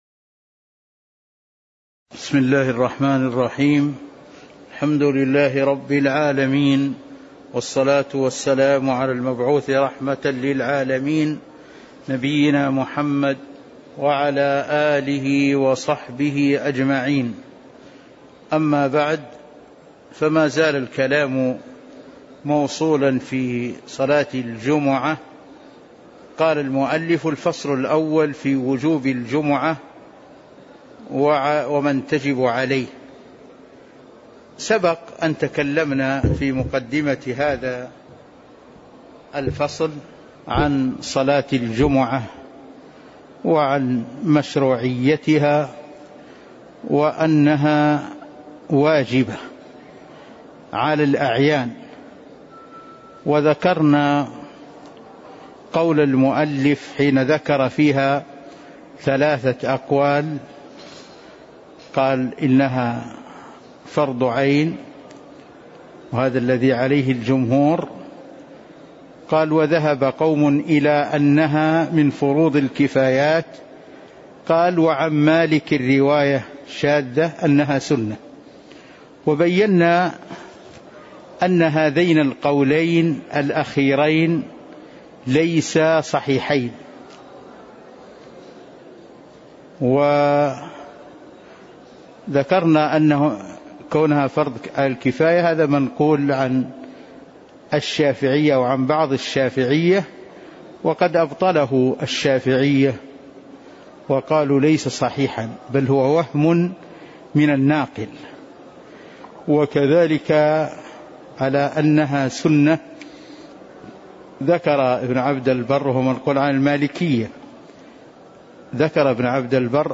تاريخ النشر ١ جمادى الأولى ١٤٤٣ هـ المكان: المسجد النبوي الشيخ